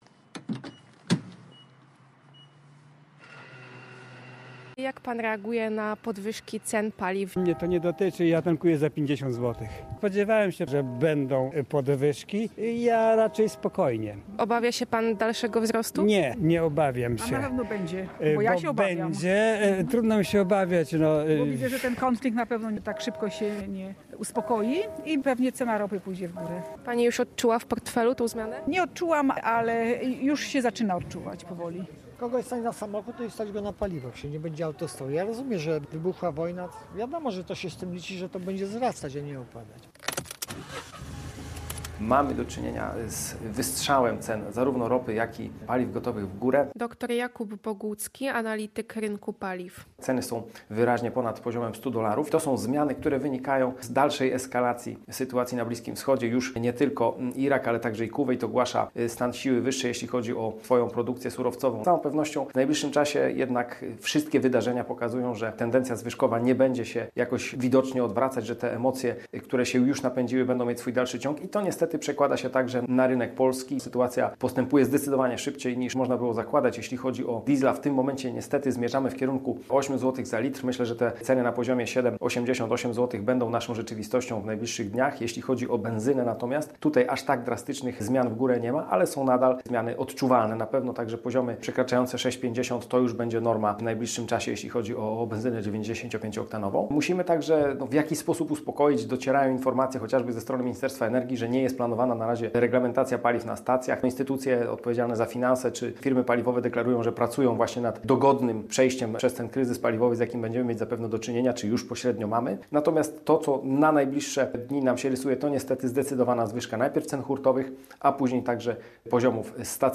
Relacje reporterskie • Cena ropy naftowej przekroczyła dziś 100 dolarów za baryłkę.